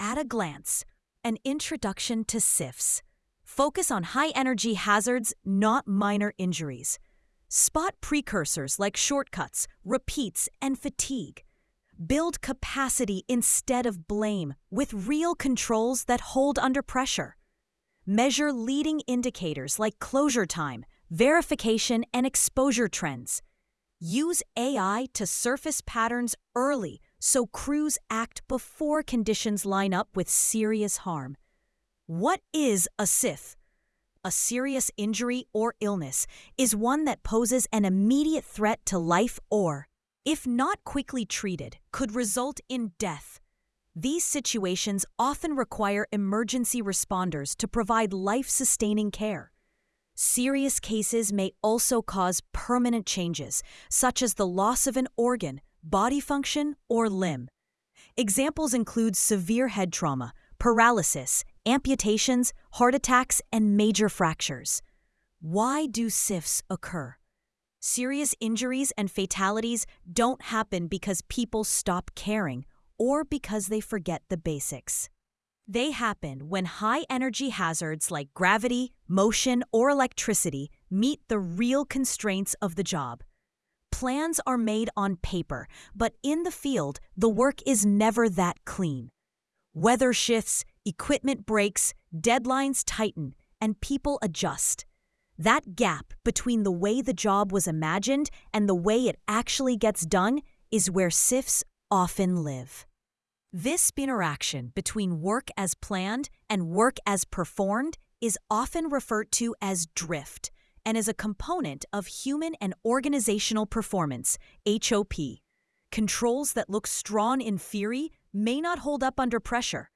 COO and Head of Safety Want me to read this to you?